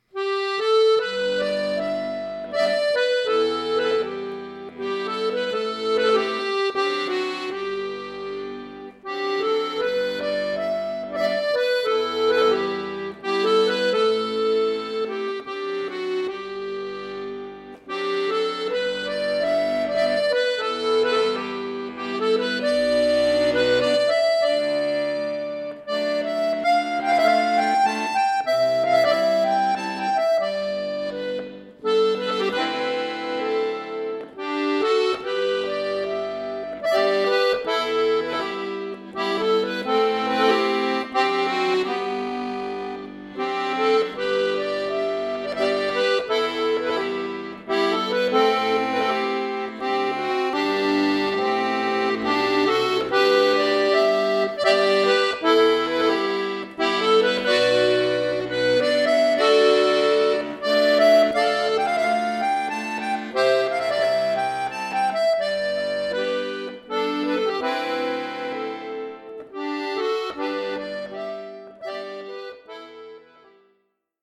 Irish
Folk